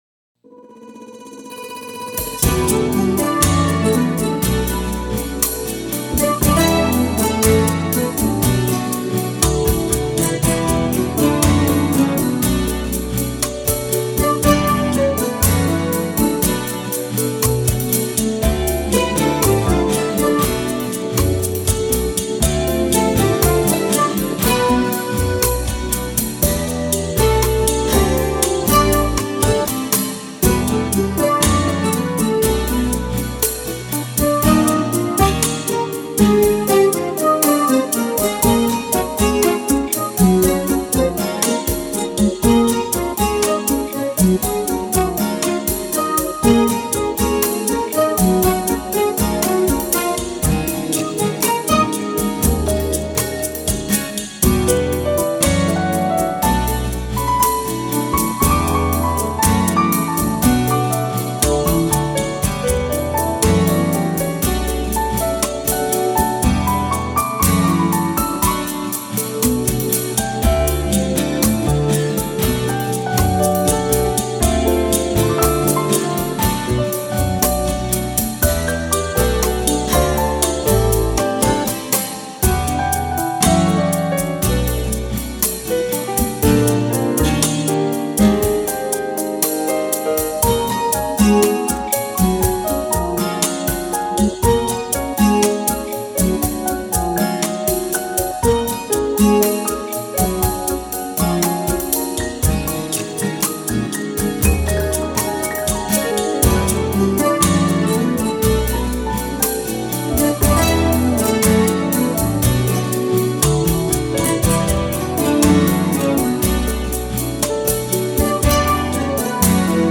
Keyboards, Accordion, Guitar, Ukulele, Bass Guitar, Drums
Trumpet, Flumpet and all Brass Instruments